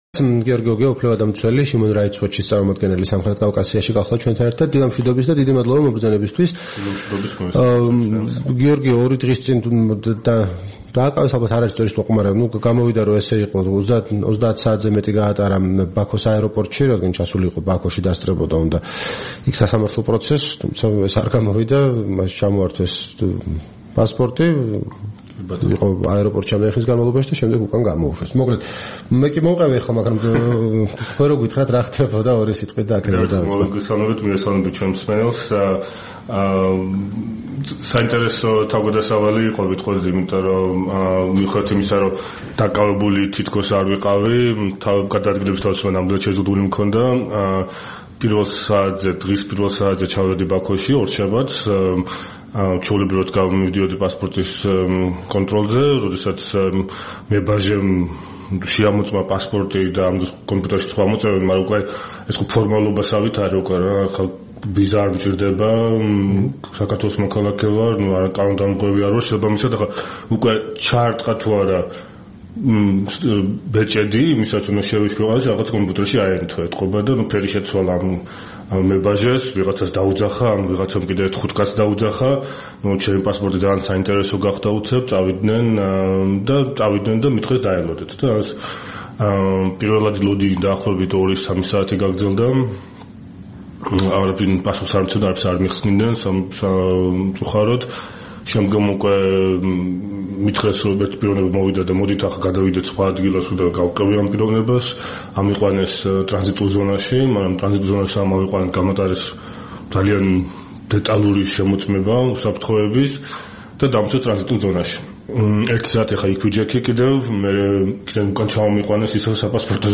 სტუმრად ჩვენს ეთერში